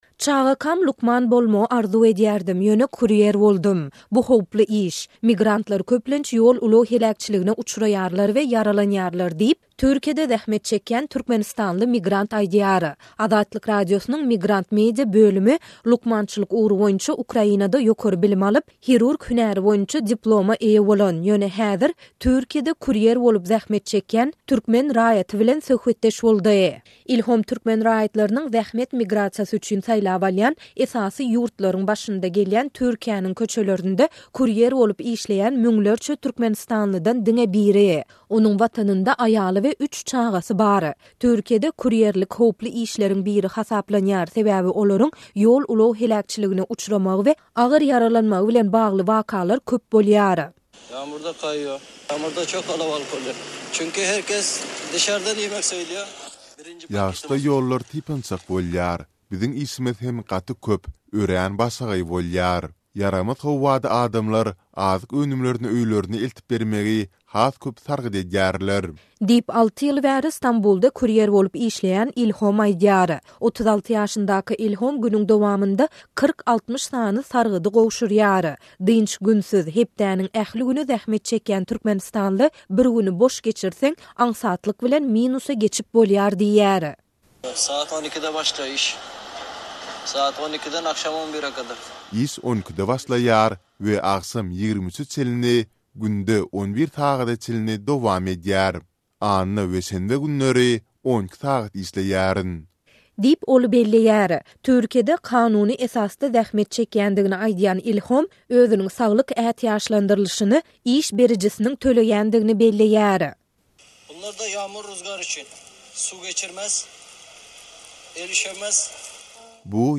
Azat Ýewropa/Azatlyk Radiosynyň Migrant Media bölümi lukmançylyk ugry boýunça Ukrainada ýokary bilim alan we hünäri boýunça hirurg bolan, ýöne häzir Türkiýede kurýer bolup zähmet çekýän türkmen raýaty bilen söhbetdeş boldy.